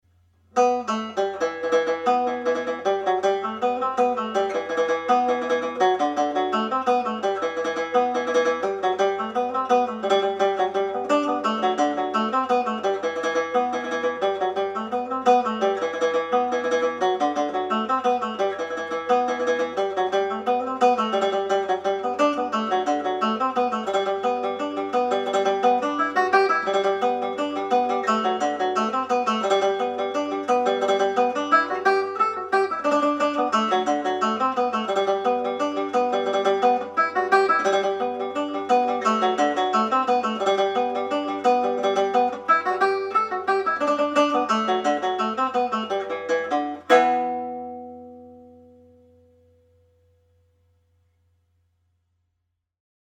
Reel (E Minor)
played at reel speed
Fermoy-Lasses_Reel-Speed.mp3